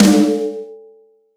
• Subby Snare Sound D Key 90.wav
Royality free snare tuned to the D note. Loudest frequency: 1085Hz
subby-snare-sound-d-key-90-XTs.wav